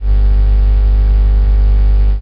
techage_hum.ogg